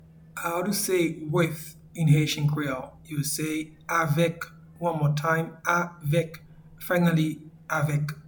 Pronunciation and Transcript:
With-in-Haitian-Creole-Avek.mp3